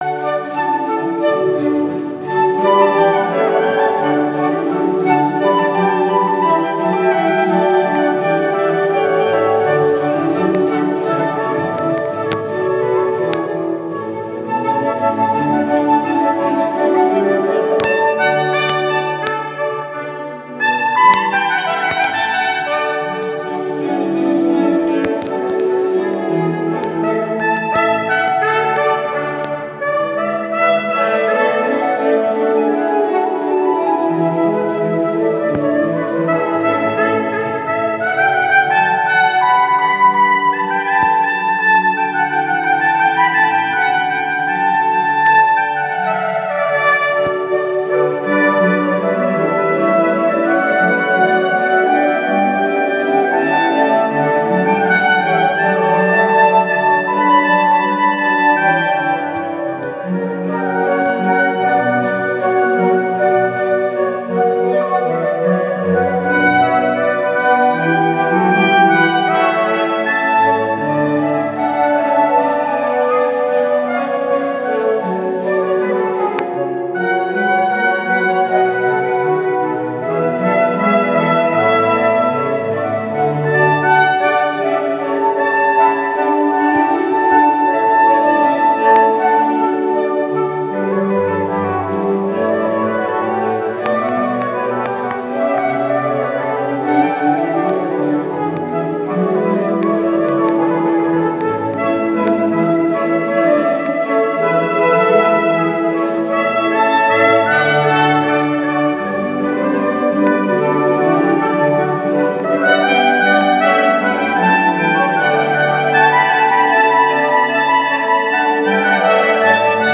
Giuseppe Torelli Concerto en r� majeur pour trompette et orchestre